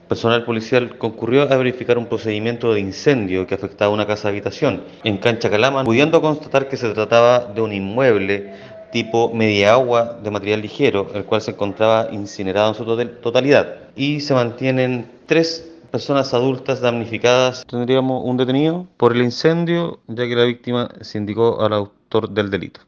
carabinero-1.mp3